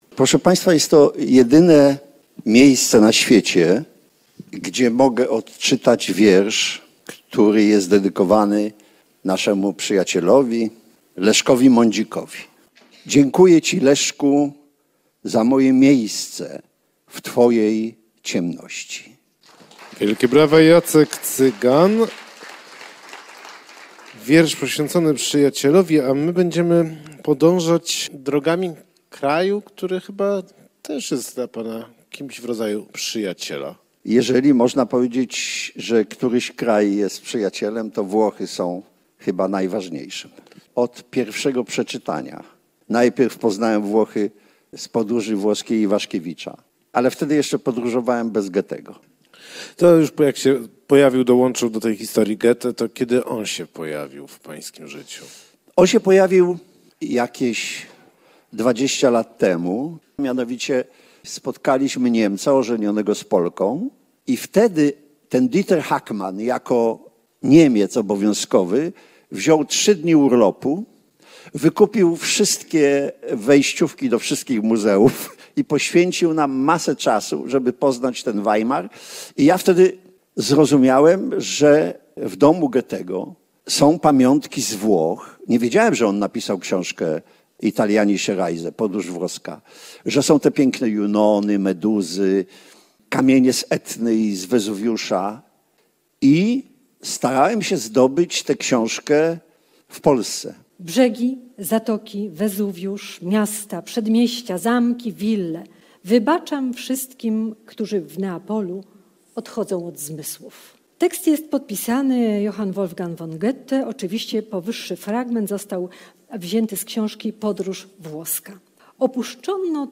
Czego dowiedział się o Goethem w trakcie blisko dwudziestoletniej wędrówki jego śladami? Odpowiedzi padały wczoraj w Teatrze Starym podczas „Bitwy o literaturę”, ale Jacek Cygan w nieoczywisty sposób rozpoczął to spotkanie – od wzruszającego, poetyckiego wątku lubelskiego…